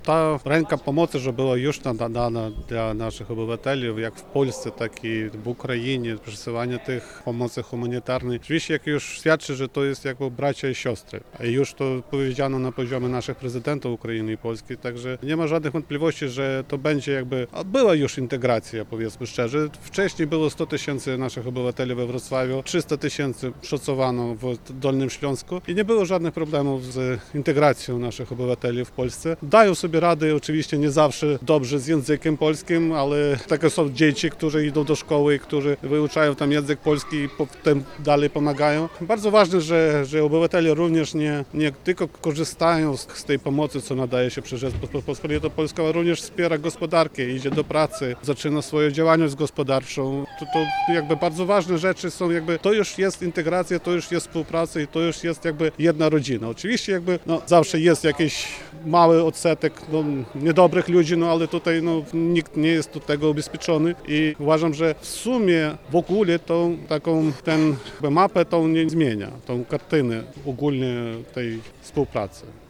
Konsul Generalny Ukrainy we Wrocławiu — Jurij Tokar, zaznaczył w rozmowie z nami, jak znacząca dla stosunków pomiędzy Polską i Ukrainą jest wyciągnięta przez Polaków pomocna dłoń.